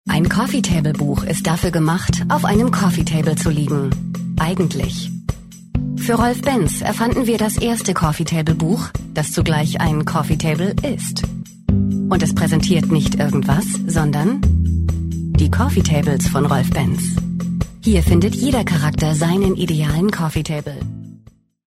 Erfahrene Sprecherin für deutsche und internationale Produktionen in zwei Muttersprachen: Deutsch und Englisch.
Sprechprobe: Industrie (Muttersprache):
Warm, sincere, young, believable, sexy, serious, elegant and vivacious - whatever you desire!